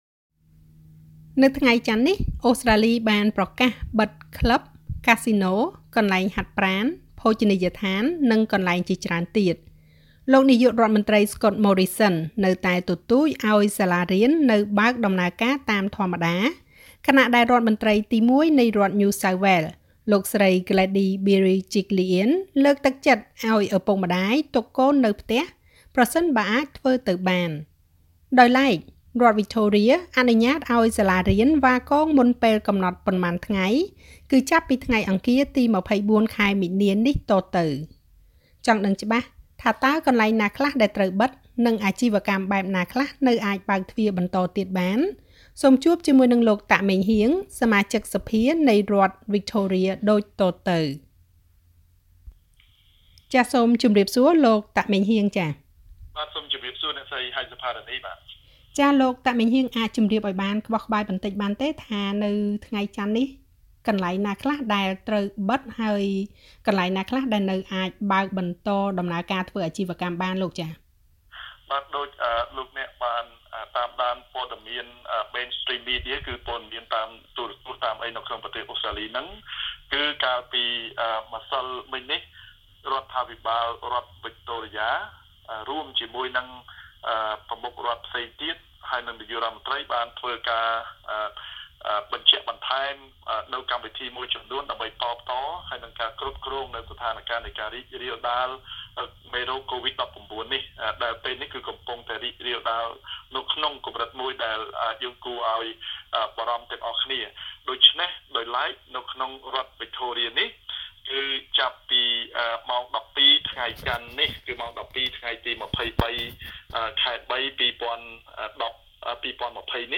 ចង់ដឹងច្បាស់ថាតើ កន្លែងណាខ្លះដែលត្រូវបិទ និងអាជីវកម្មបែបណាខ្លះនៅអាចបើកទ្វារបន្តទៀតបាន សូមចុចសំឡេងស្តាប់បទសម្ភាសរវាងSBS ខ្មែរជាមួយនិង លោកតាក ម៉េងហ៊ាង សមាជិកសភានៃរដ្ឋវិចថូរៀដូចតទៅ។